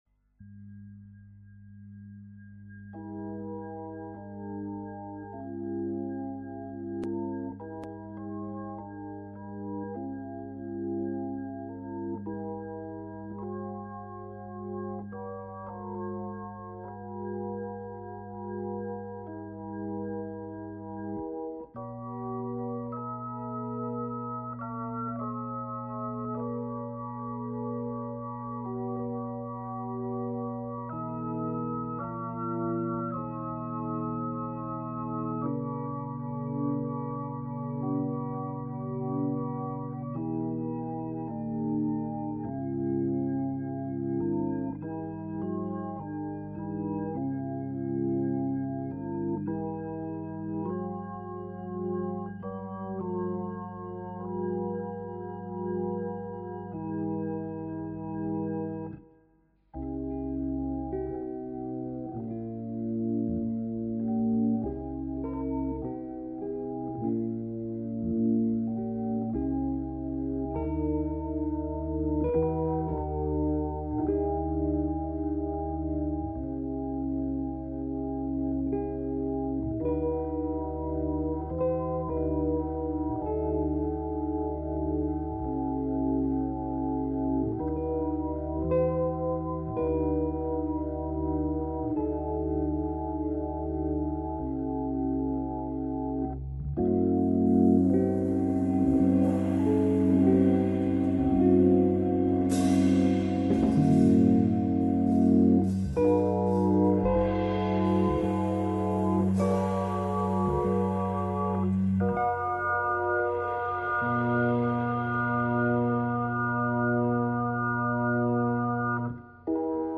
absolument pas retravaillé au mix et donc un peu "brut" encore.
Effectivement c’est pas du jazz , rock ou classique mais c’est…..comment dire…. très envoûtant, un peu psychédélique à la Pink Floyd.
J'aime bien cette atmosphère musicale..... après le piano, je vais bientôt pouvoir me mettre à l'orgue .......
Style assez "avant gardiste" mais pas désagréable.
Bon, si je devais employer un qualificatif, je dirais "planant".